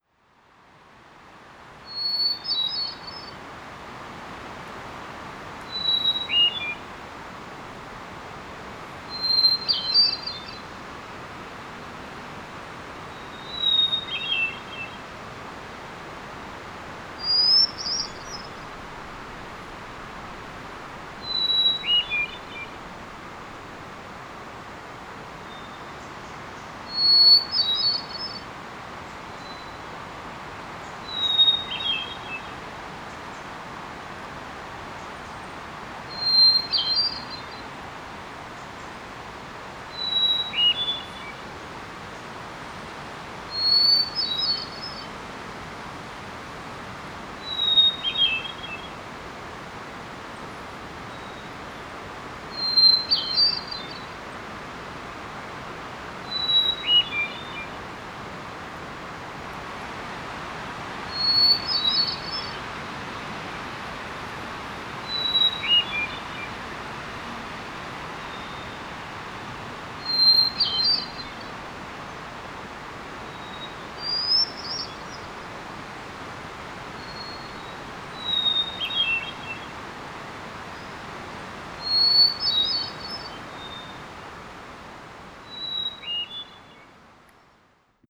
Recordings from Juan de Fuca Provincial Park
53. Hermit Thrush from the path to Second Beach
53_Hermit_Thrush.wav